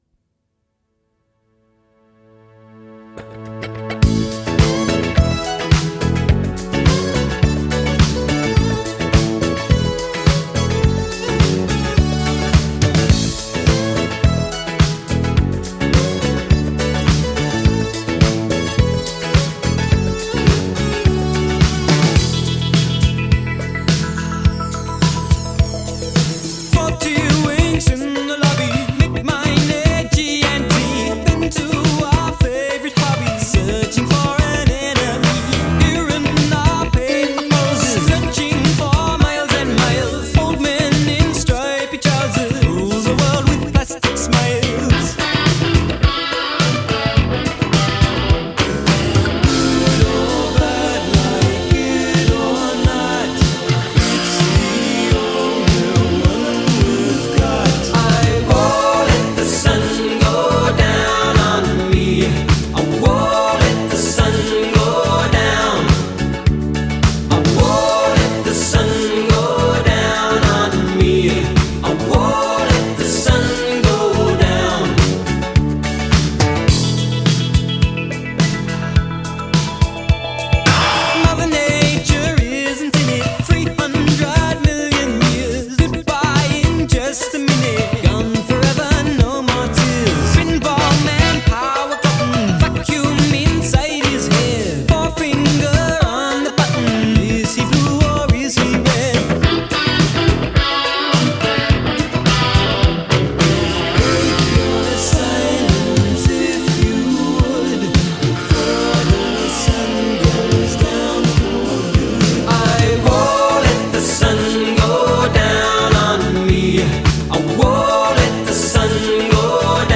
Pop, Synth-Pop